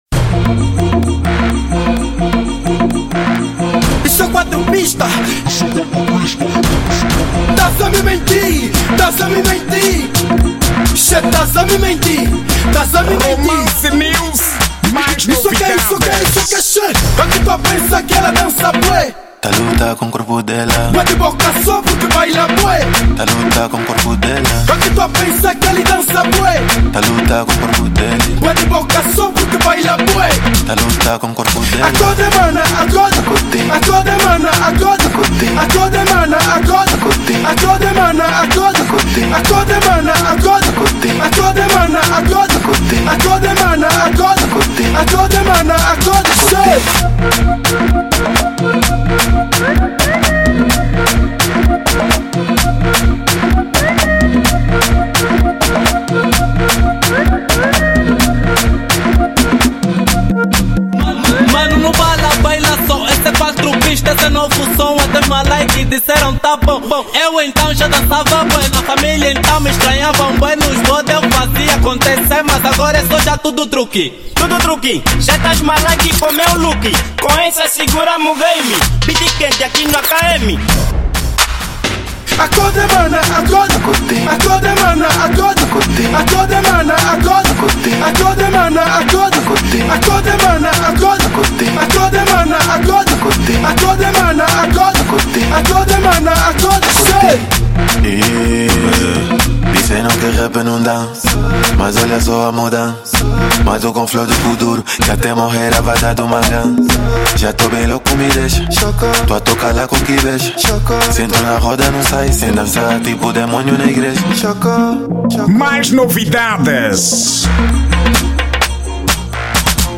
Estilo: Afro House